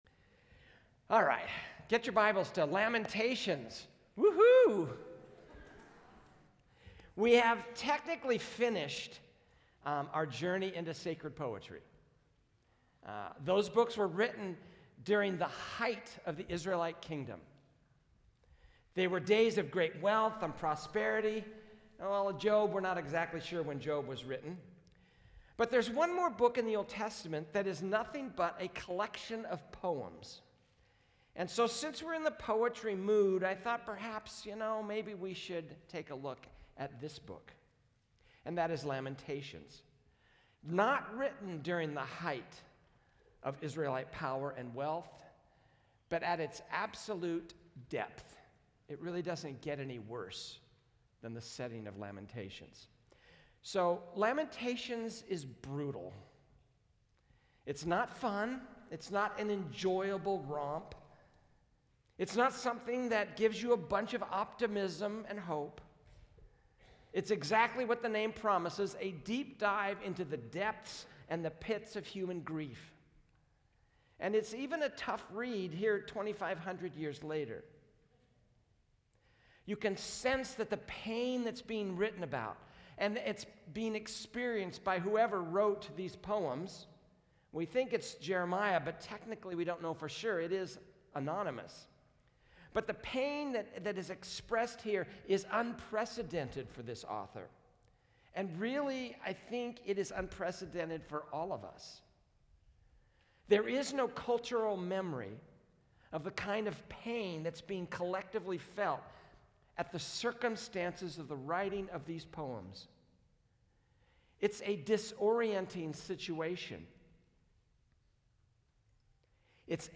A message from the series "Timeless."